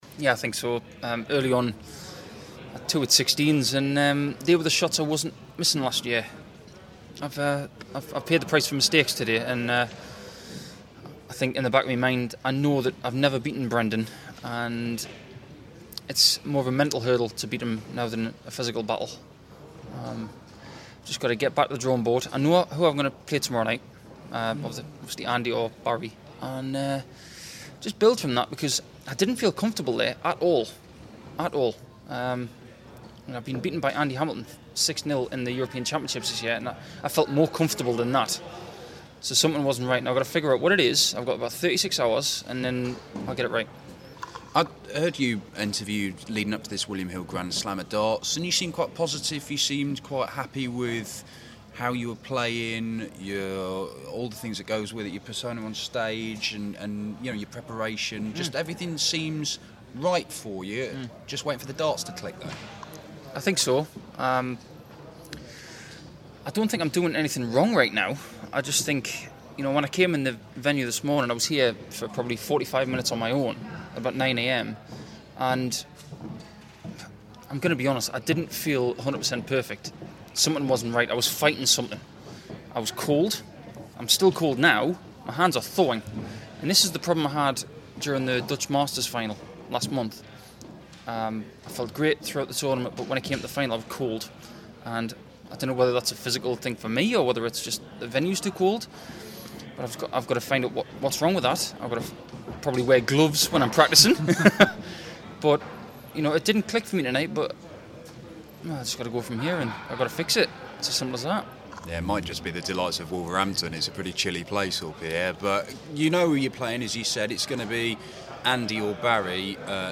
William Hill GSOD - Nicholson Interview